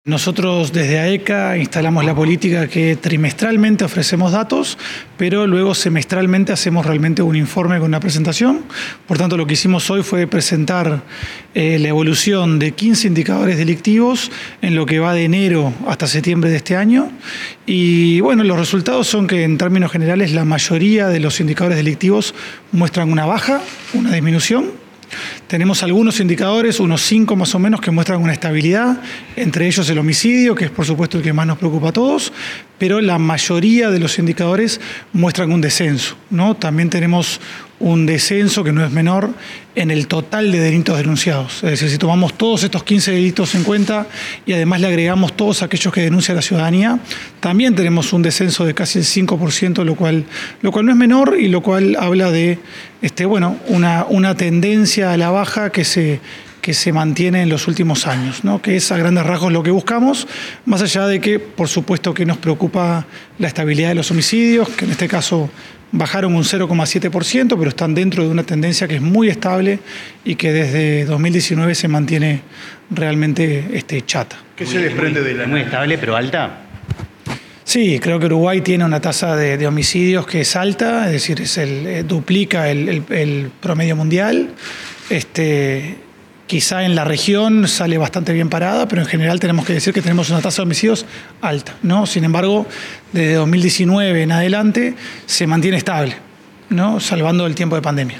Declaraciones del gerente de AECA
Declaraciones del gerente de AECA 13/10/2025 Compartir Facebook X Copiar enlace WhatsApp LinkedIn El gerente del área de Estadística y Criminología Aplicada del ministerio del Interior, Diego Sanjurjo, brindó una conferencia de prensa para informar sobre indicadores delictivos, entre enero y setiembre 2025.